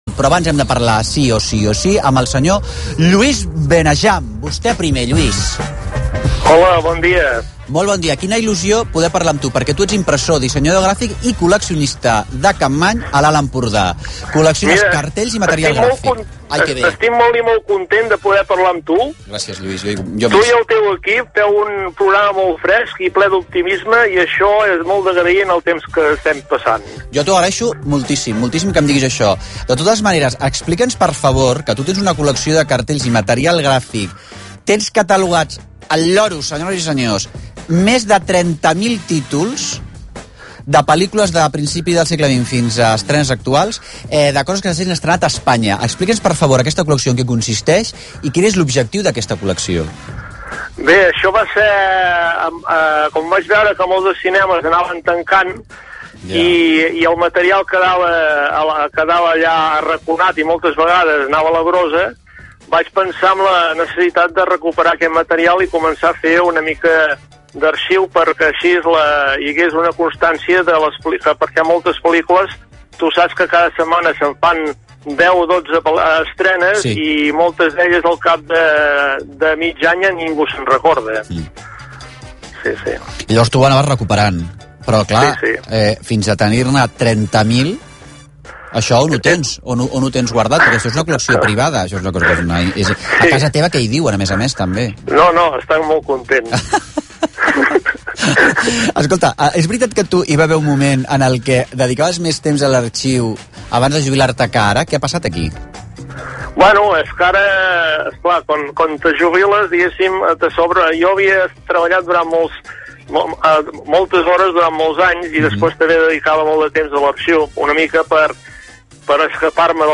Marc Giró i Costa presenta a diario un programa en la emisora RAC-1. Este programa lleva por título USTED PRIMERO y Existía un apartado donde los oyentes podían hacer comentarios. El día 10 de noviembre de 2020 Marc me hizo una corta entrevista en la que hablamos del archivo de cine que estoy realizando.